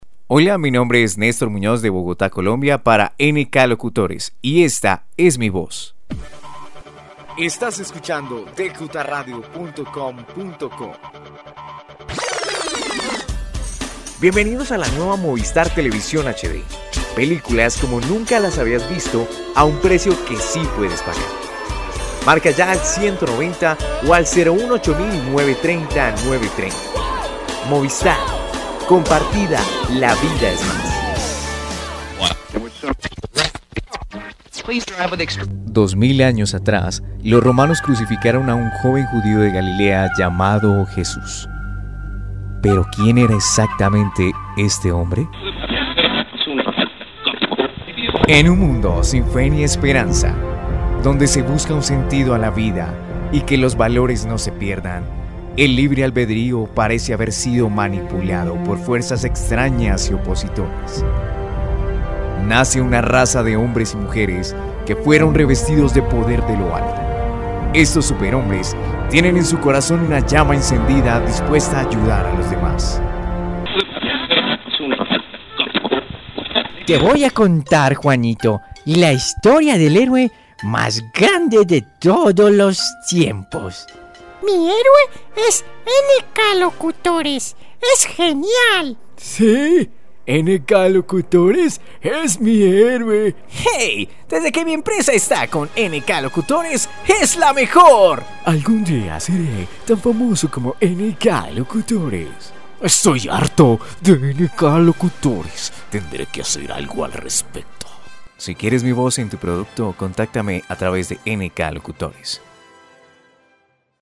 Locutor Comercial de Bogota Colombia.
Sprechprobe: Sonstiges (Muttersprache):